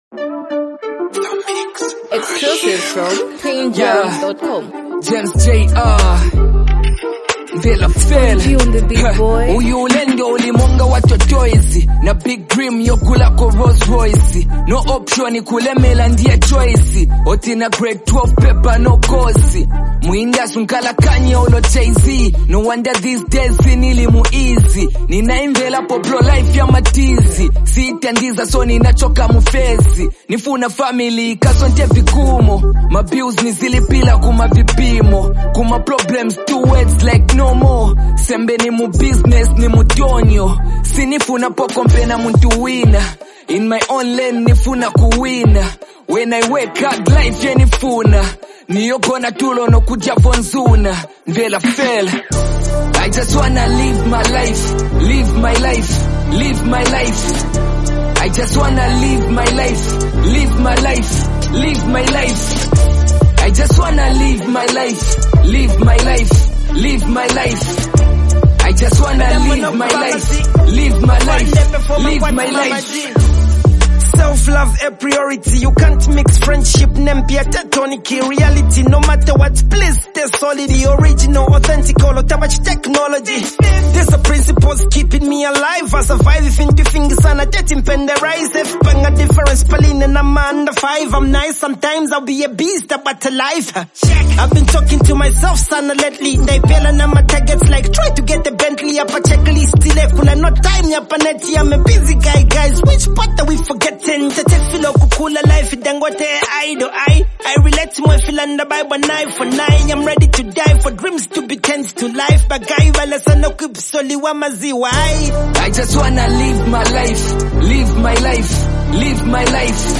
a deeply reflective and emotionally layered song
calm yet powerful, with a laid-back instrumental